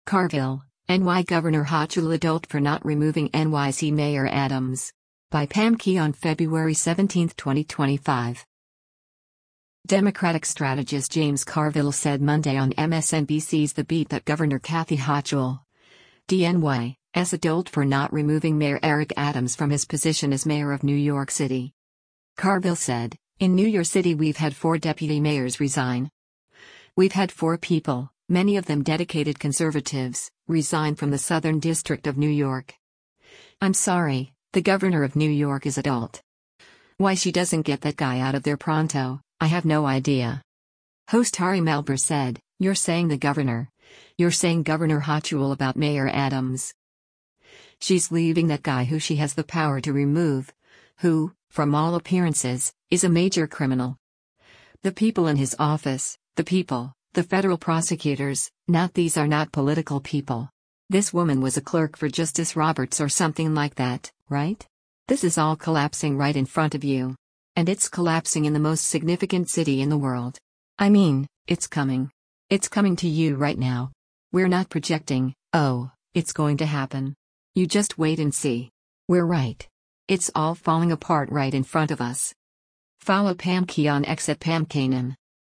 Democratic strategist James Carville said Monday on MSNBC’s “The Beat” that Governor Kathy Hochul (D-NY) s a “dolt” for not removing Mayor Eric Adams from his position as mayor of New York City.